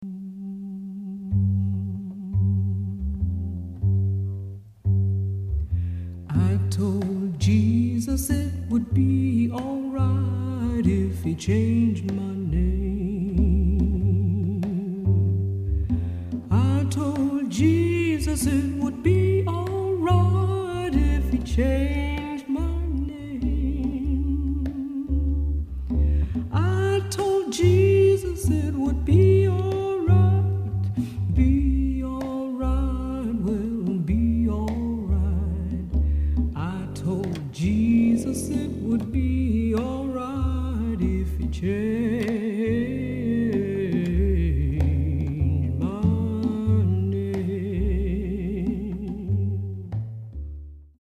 Voicing: Solo